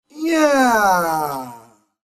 Play, download and share Yeeehhhh original sound button!!!!